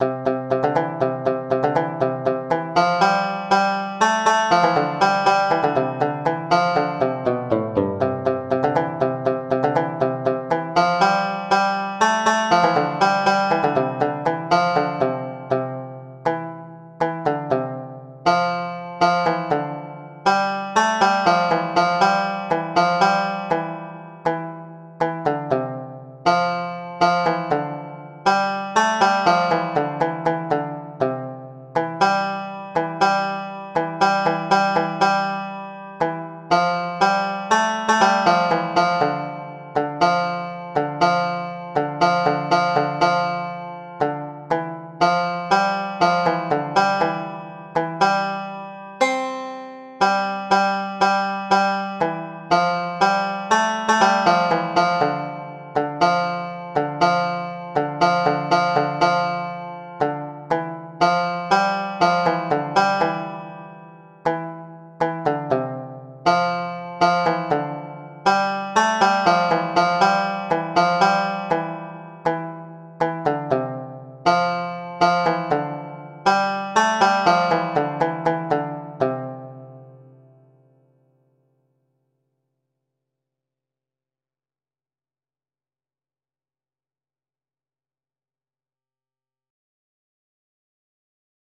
C major ♩= 120 bpm